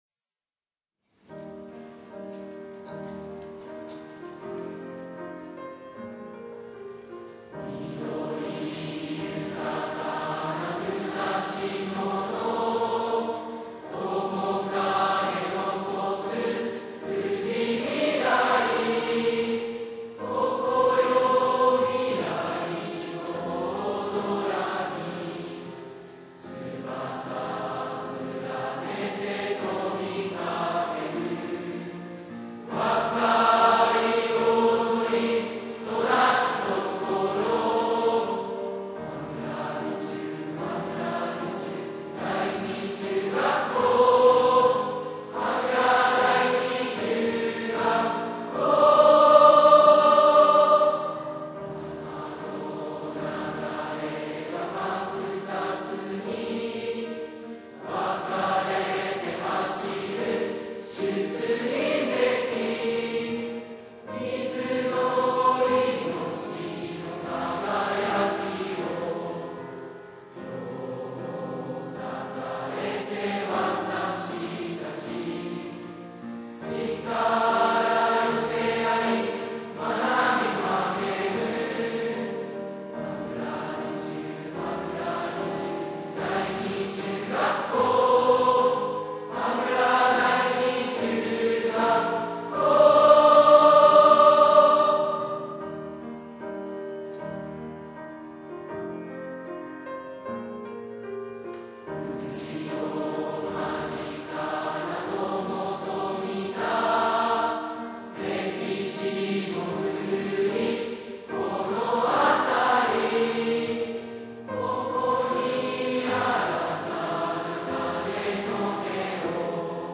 クリックすると合唱を再生します。